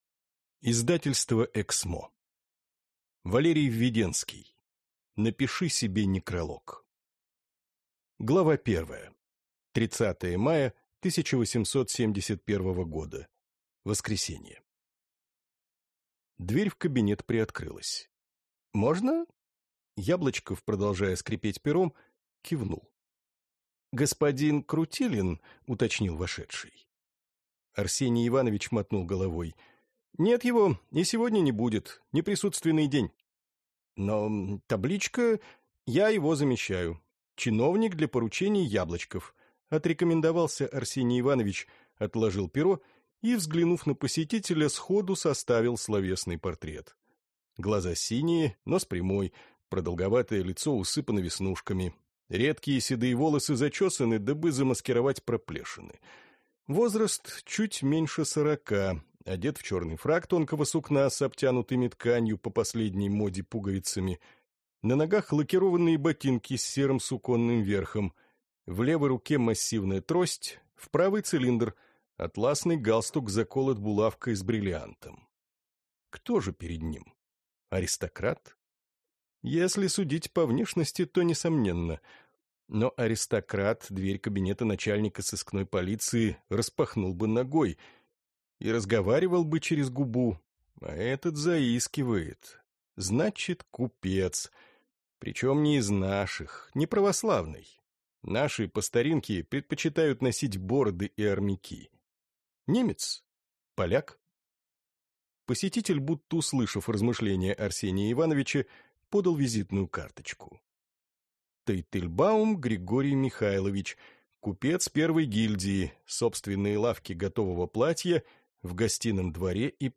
Аудиокнига Напиши себе некролог | Библиотека аудиокниг